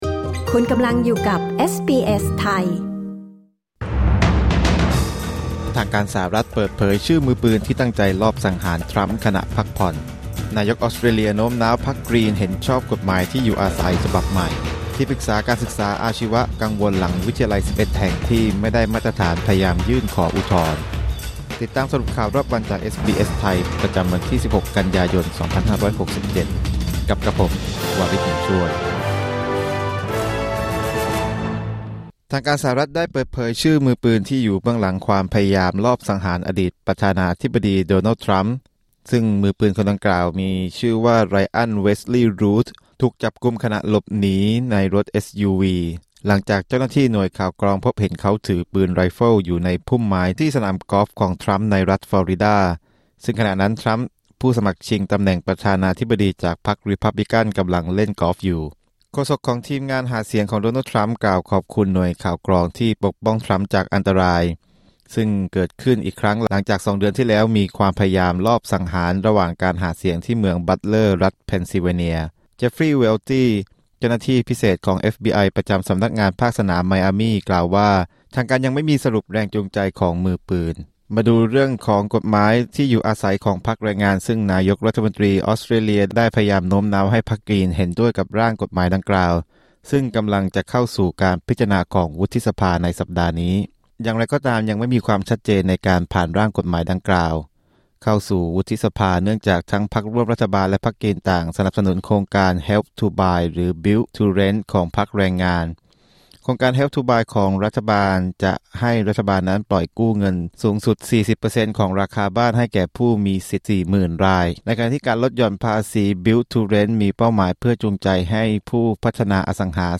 สรุปข่าวรอบวัน 16 กันยายน 2567